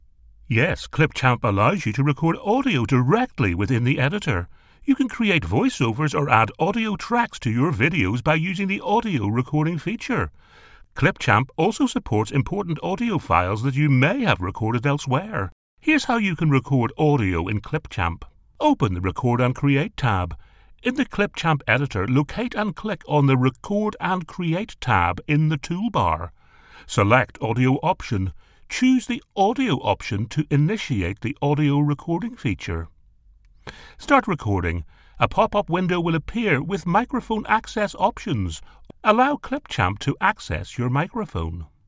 Male
Storytelling ,Baritone , Masculine , Versatile and Thoughtful . Commercial to Corporate , Conversational to Announcer . I have a deep, versatile, powerful voice, My voice can be thoughtful , authoritative and animated . Confident and able to deliver with energy , humorous upbeat and Distinctive . Animated
Explainer Videos